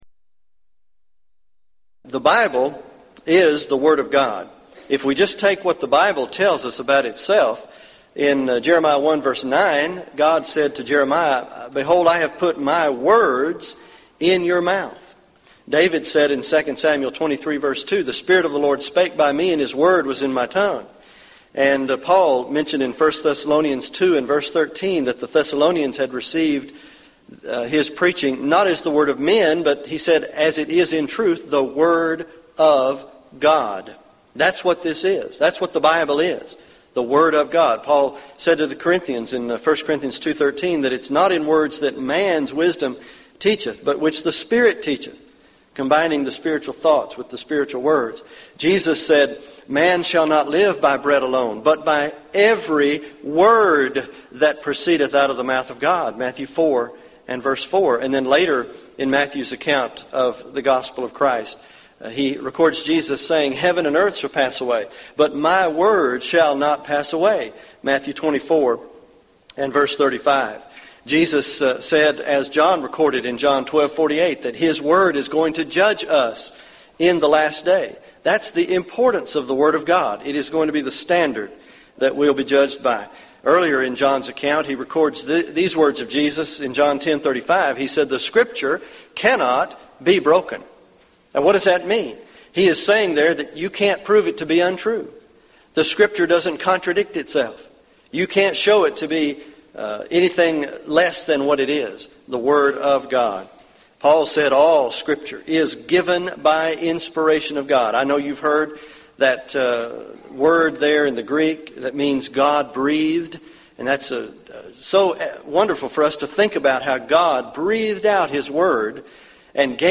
Event: 1999 Gulf Coast Lectures
lecture